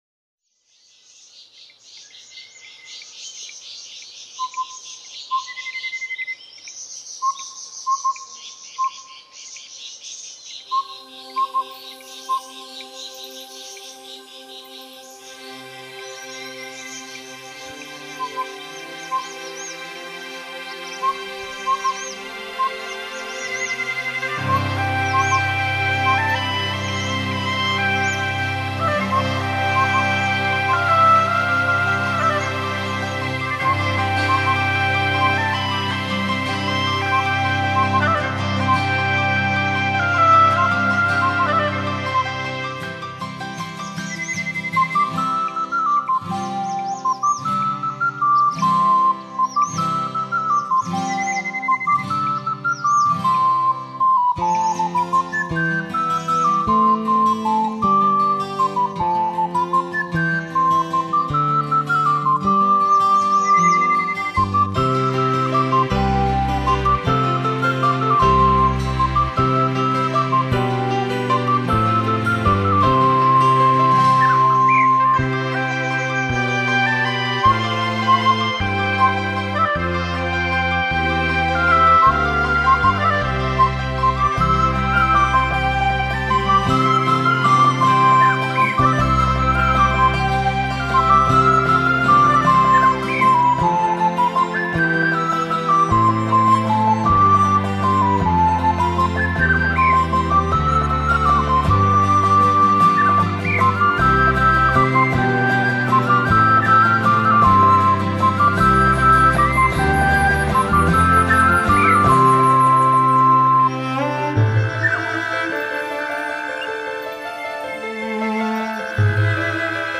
自然音乐、器乐演奏、跨界、New Age、
民族音乐、宗教音乐全都录